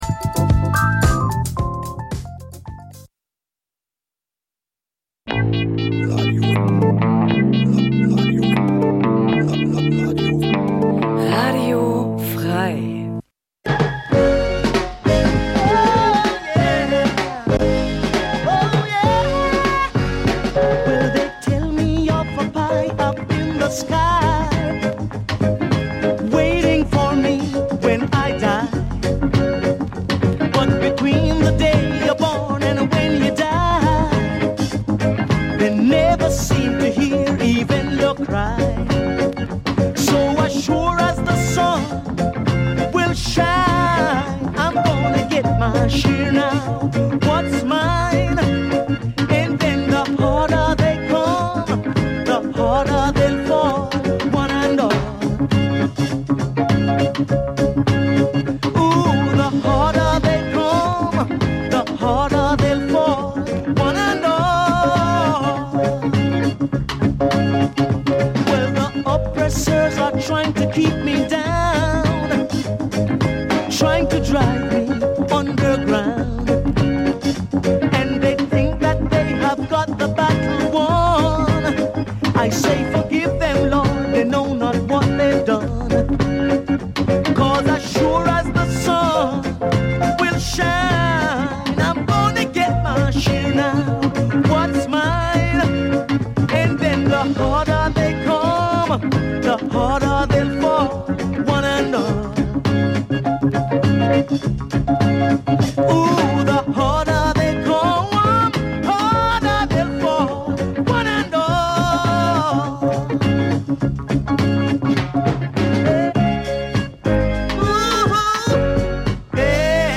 Schr�ge Originale stehen noch schr�geren Coverversionen gegen�ber.
So entdecken wir f�r unsere H�rer musikalische Grausamkeiten genauso, wie "Unerh�rtes" von einst und heute. Wir w�nschen gute Schallplattenunterhaltung und guten Empfang.
Musik vergangener Tage Dein Browser kann kein HTML5-Audio.